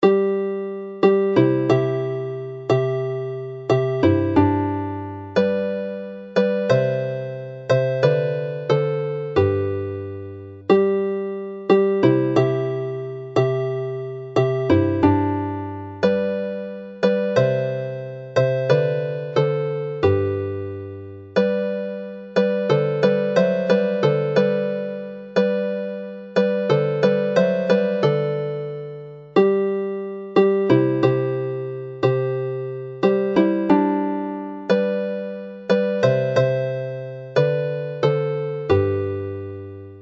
This seasonal set starts with a well-known Plygain carol,  Daeth Nadolig (Christmas came) sung to the plaintive Welsh tune Deio Bach (little Deio).